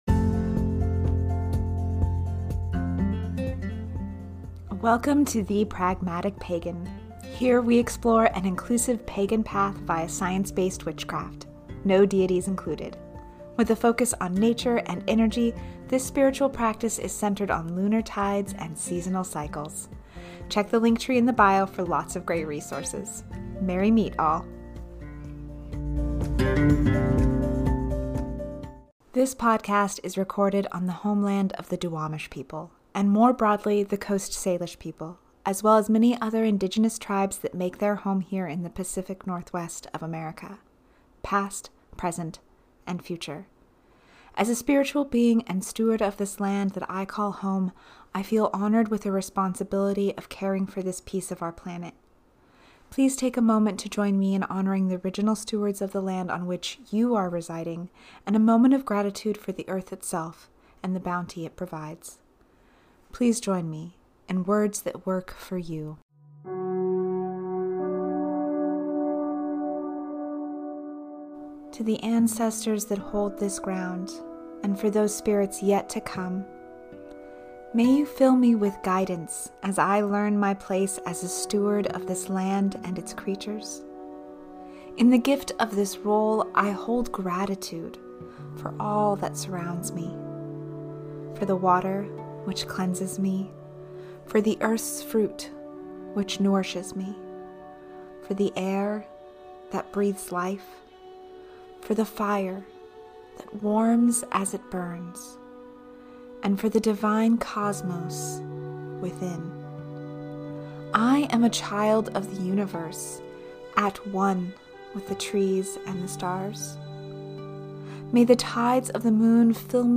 We celebrate this Harvest Full Moon with an Abundance Jar Spell. Join me for this guided ritual.